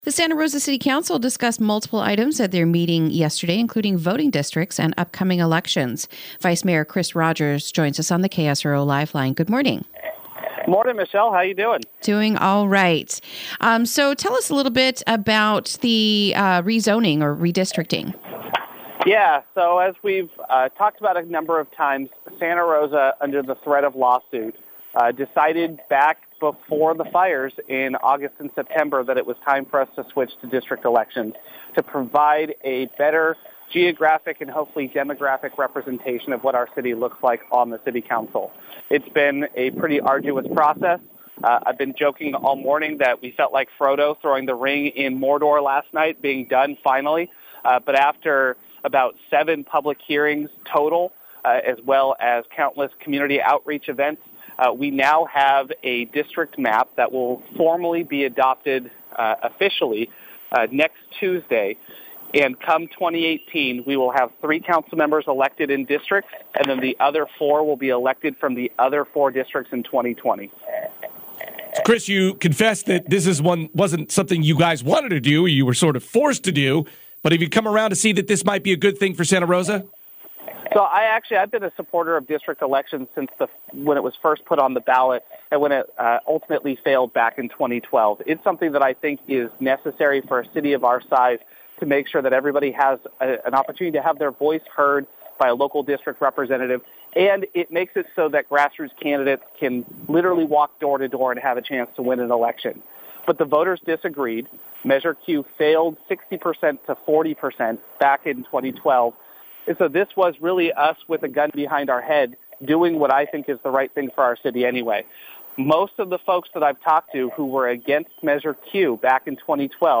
Interview: The New Redistricting Map and It’s Effect on Santa Rosa Voters